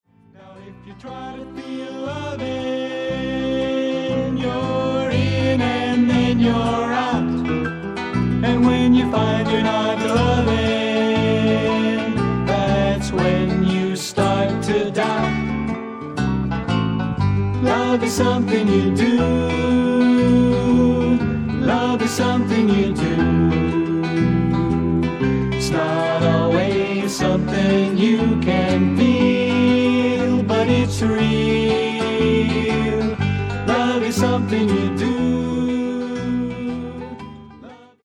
SOFT ROCK / GARAGE